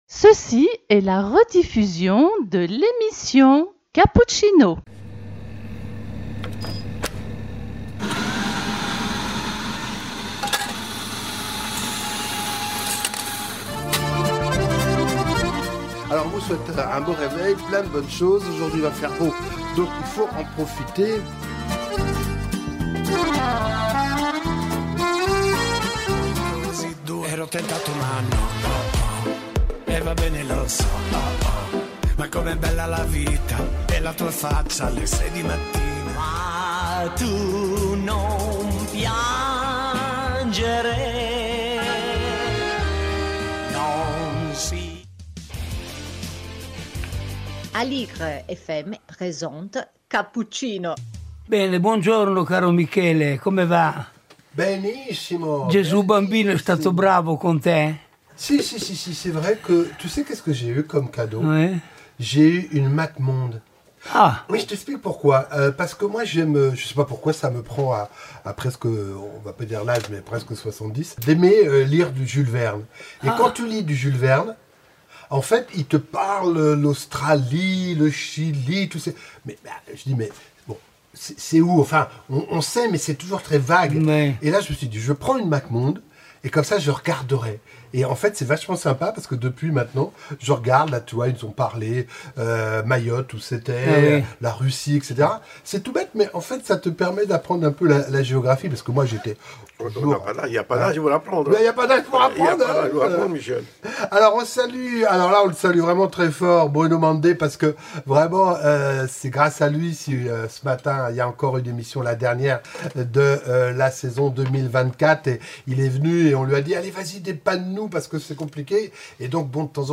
Emission de fin d'année avec les chansons, annonces et rubriques habituelles.